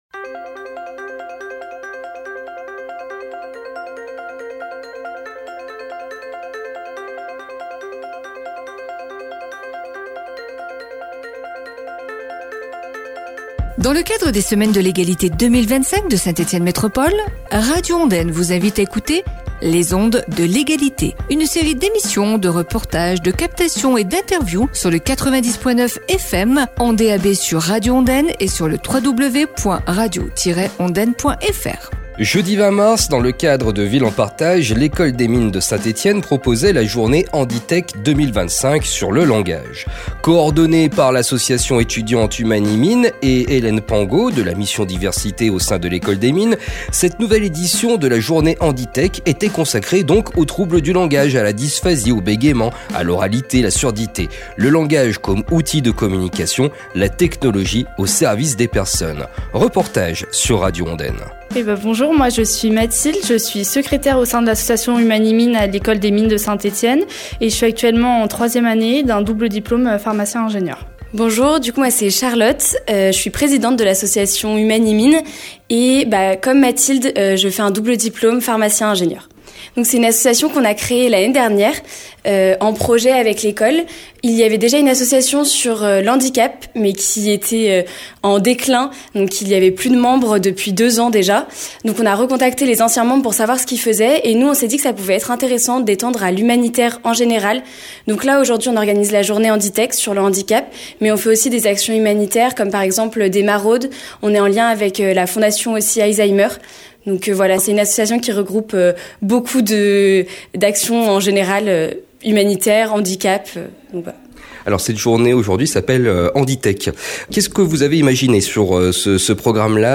Reportage à écouter aujourd’hui sur Radio Ondaine